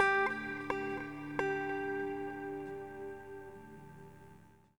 34c-gte-68Dmin.wav